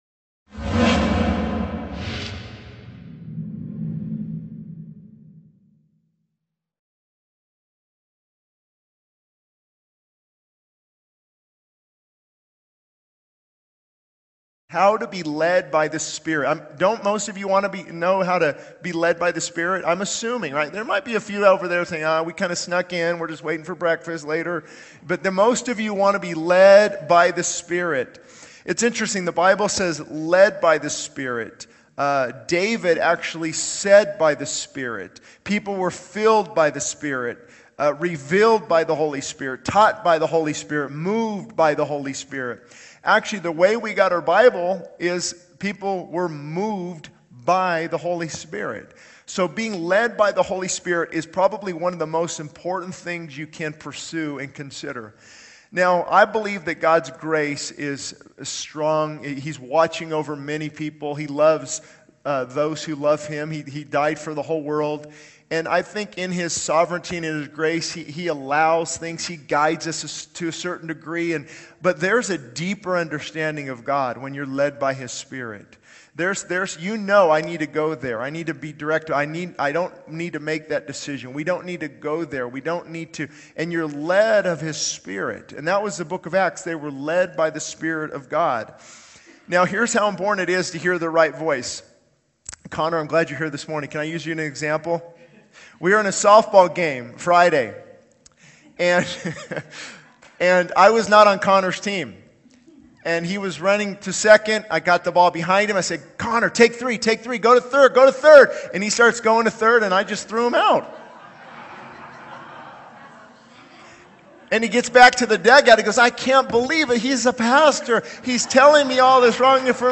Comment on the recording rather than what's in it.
He encourages the congregation to open their ears to hear the Spirit's leading and to engage in ministry as a way to discern God's will.